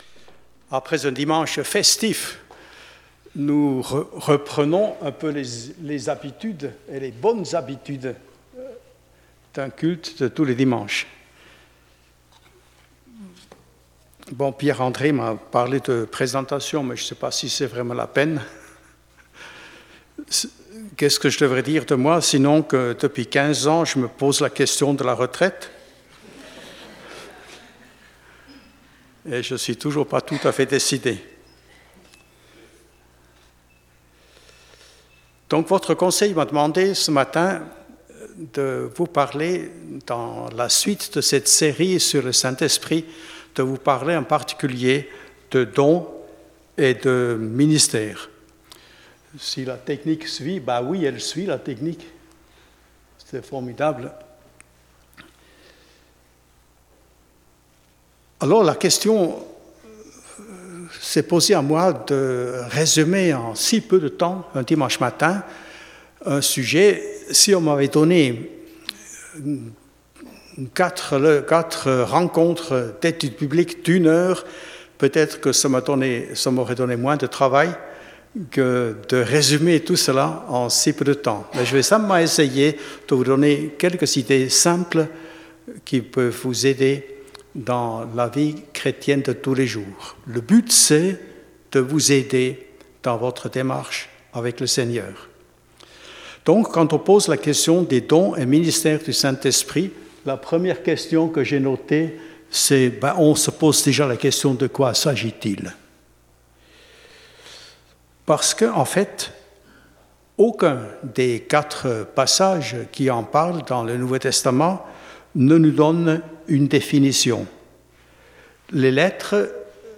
Ecoutez les différents messages de l'église évangélique de Bouxwiller